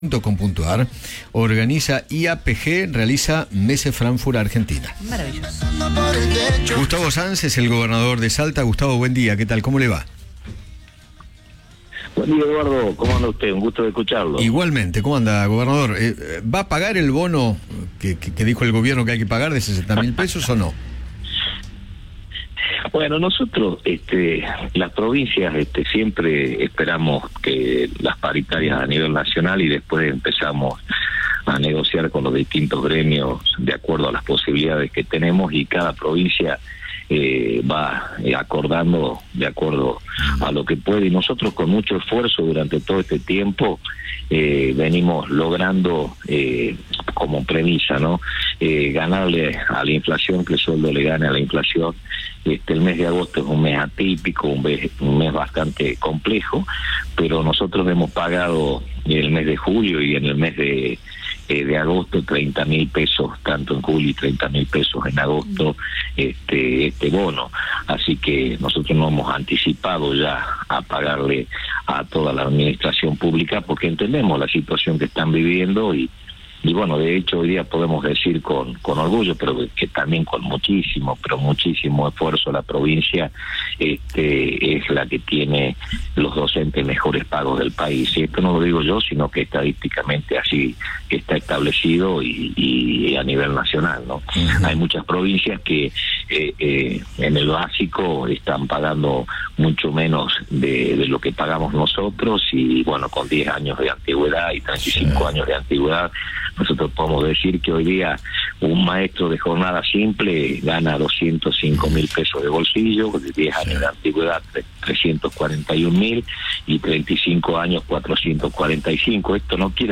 Gustavo Sáenz, gobernador de Salta, habló con Eduardo Feinmann sobre la decisión de no pagar el bono dispuesto por el Gobierno Nacional, al igual que lo hicieron otras 13 provincias.